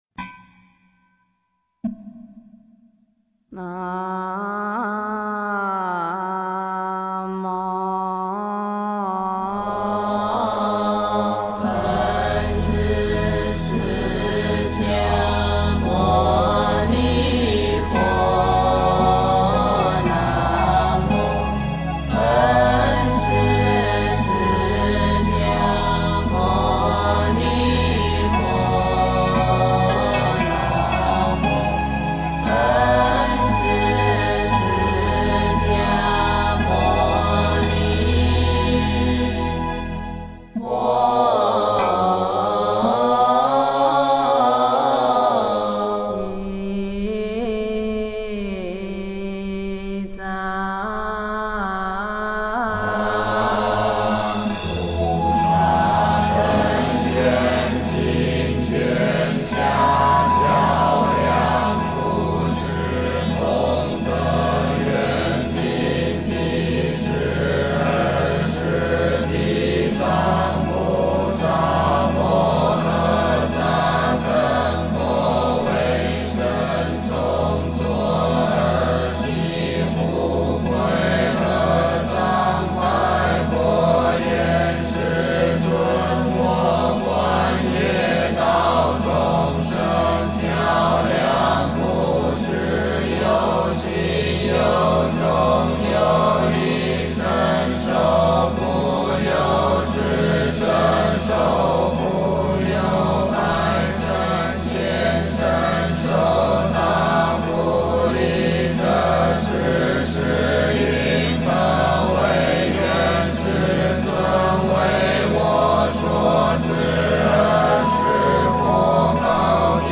地藏经下卷 诵经 地藏经下卷--如是我闻 点我： 标签: 佛音 诵经 佛教音乐 返回列表 上一篇： 忏悔发愿文 下一篇： 佛宝赞 相关文章 寒鸭戏水--中央民族乐团 寒鸭戏水--中央民族乐团...